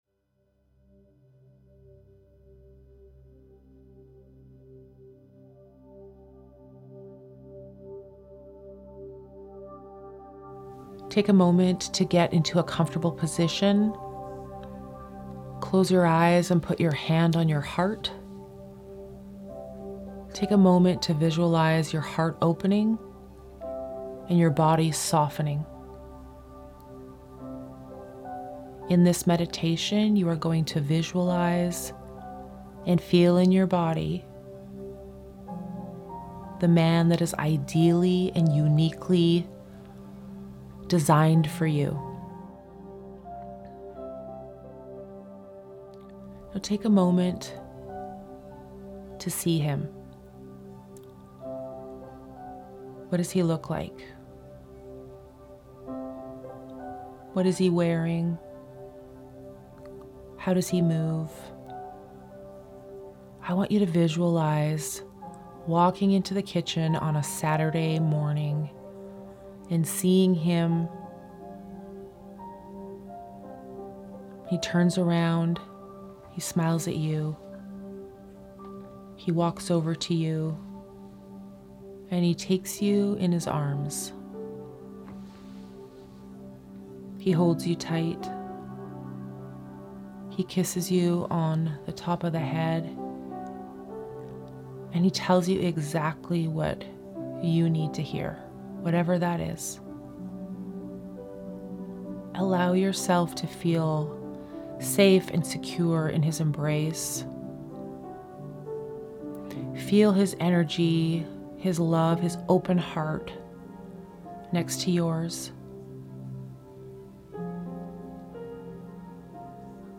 Dating Webinar - Meditation